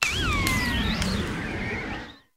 bramblin_ambient.ogg